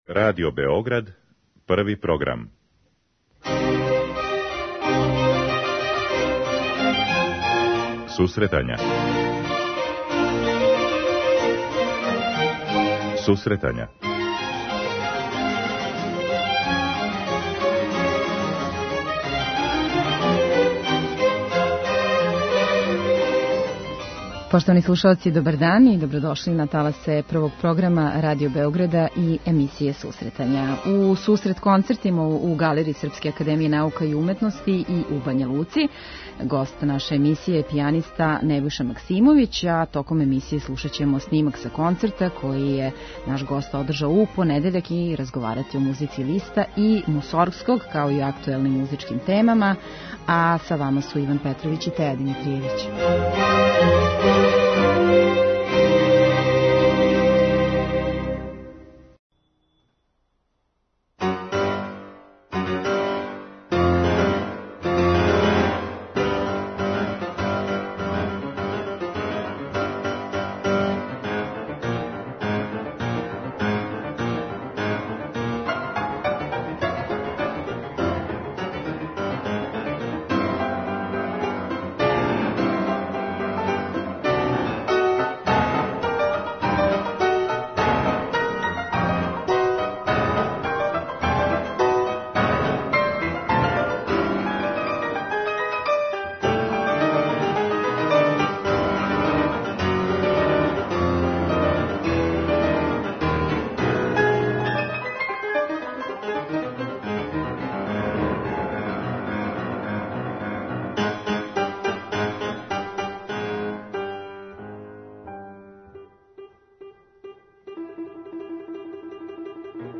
Током емисије слушаћемо снимак са концерта који је одржан у понедељак и разговарати о музици Листа и Мусоргског као и актуелним музичким темама.